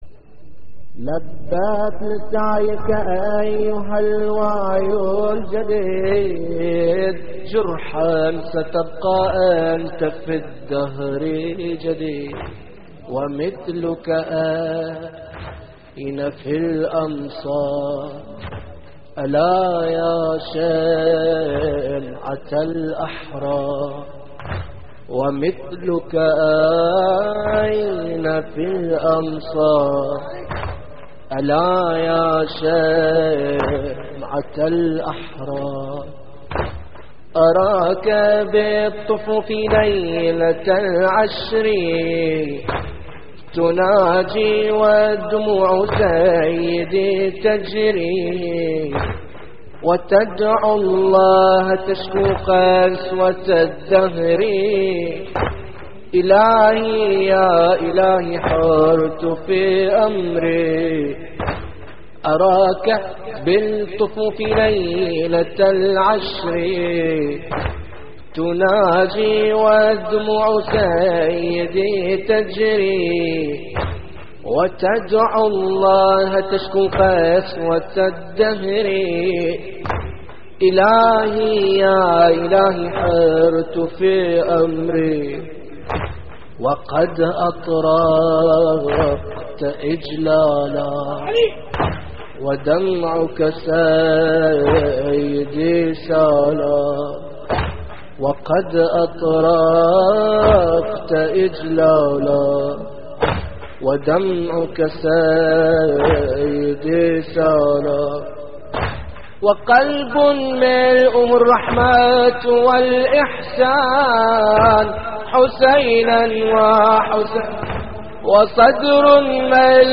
انصارٌ كحبيب ابن مظاهر وزهير ابن القين (3) / الرادود
اللطميات الحسينية